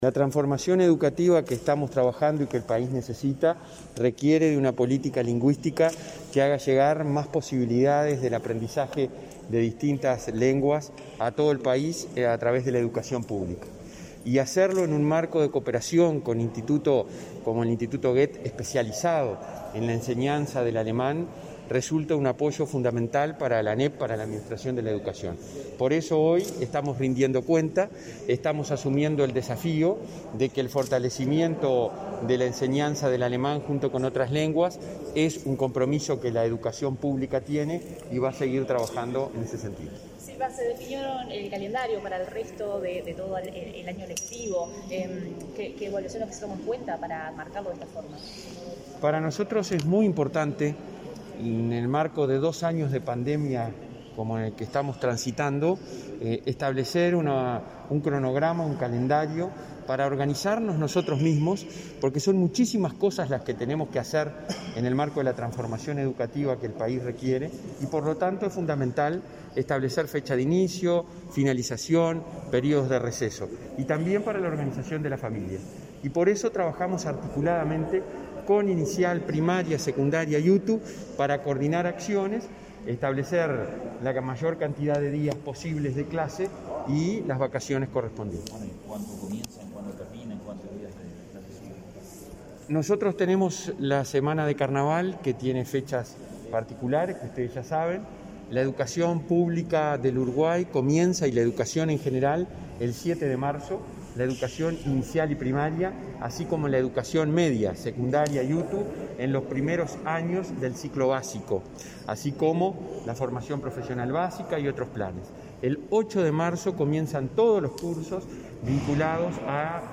Declaraciones del presidente del Codicen, Robert Silva, a la prensa